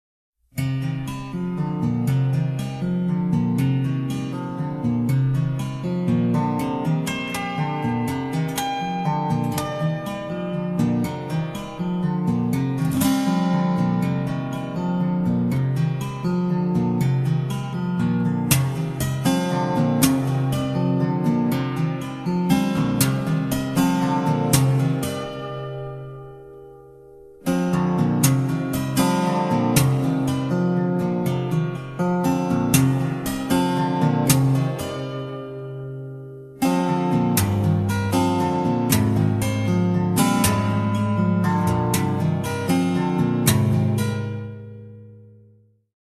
Studio album by